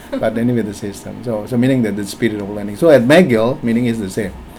S1 = Indonesian male S2 = Malaysian female
S1 : ... but anyway the system. so so meaning that the spirit of learning . so at mcgill (.) meaning is the same Intended Words: spirit of learning Heard as: speed of learning Discussion: There is no [t] at the end of spirit and the word is said quite fast, so that it sounds like one syllable. There is a dip in the third formant to suggest a medial [r]; but it is spoken too fast to enable it to be clearly perceived.